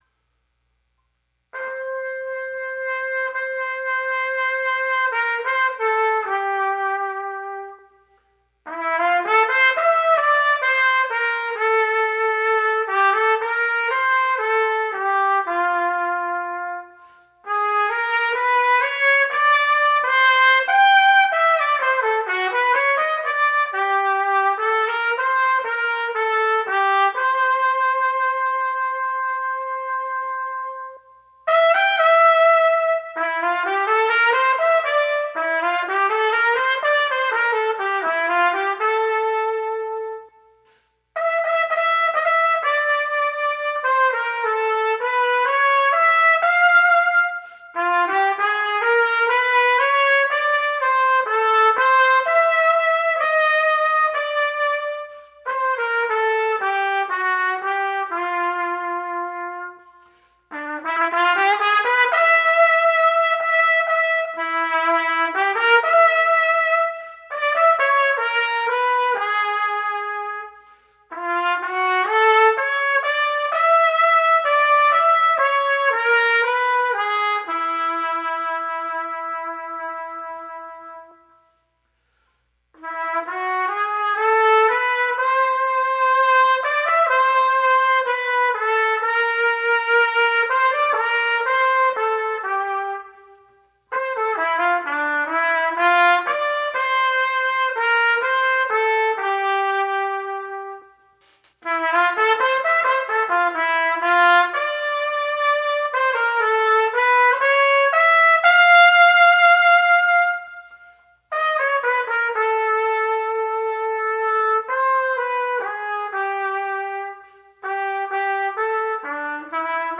Trumpet
Ballad Etude
Complete Performance
ballad-set-1-v3.m4a